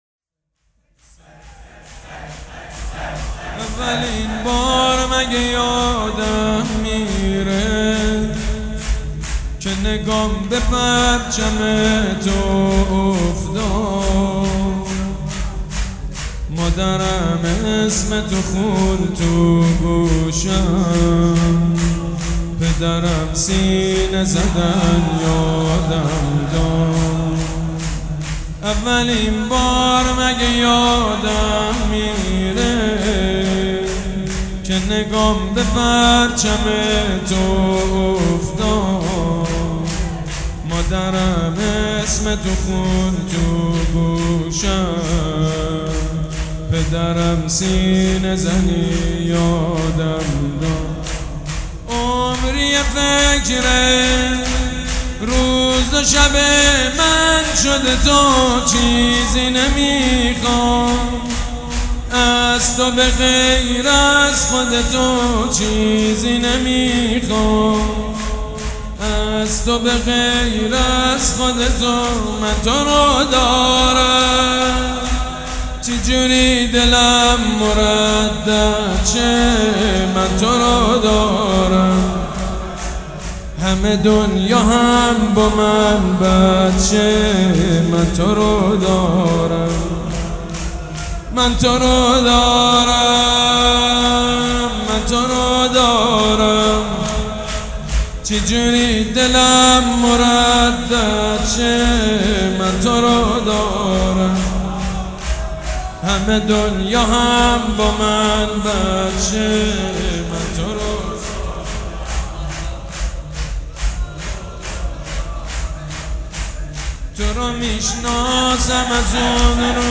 شور
مداح
حاج سید مجید بنی فاطمه
مراسم عزاداری شب چهارم